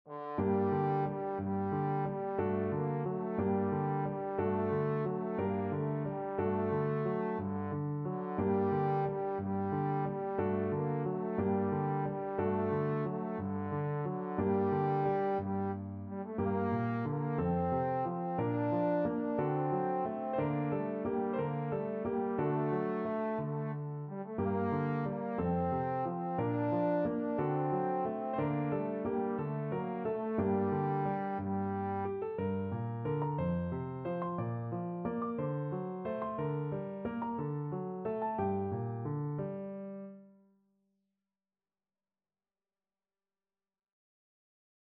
Trombone version
~ = 90 Munter
6/8 (View more 6/8 Music)
Classical (View more Classical Trombone Music)